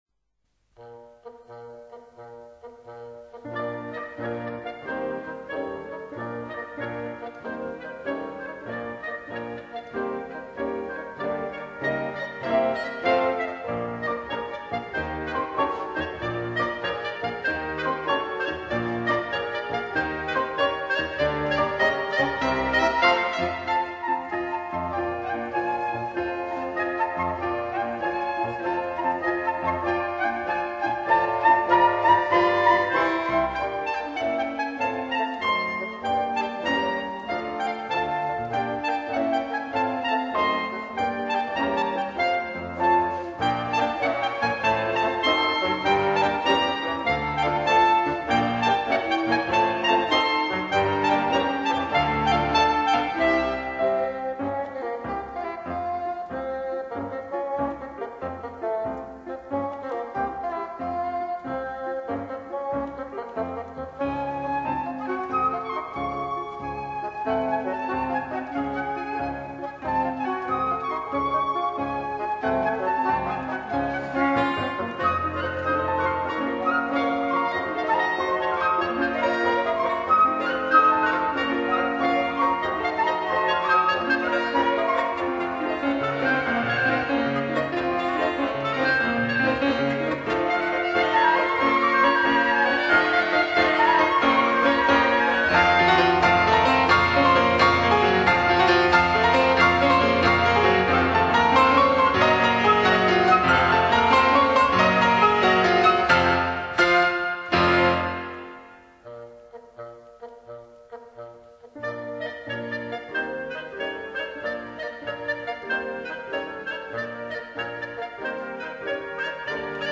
Concerto for Trombone and Wind Orchestra
Variations for Oboe and Wind Orchestra
Concerto for Clarinet and Wind Orchestra